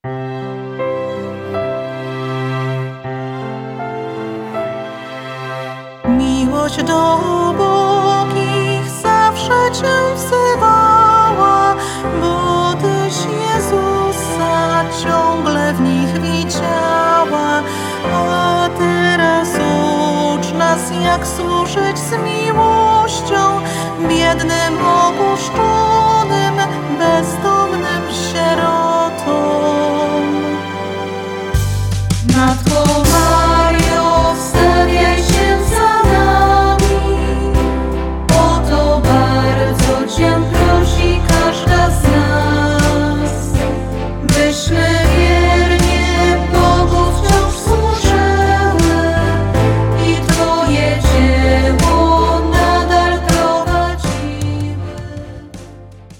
ŚPIEW I CHÓRKI: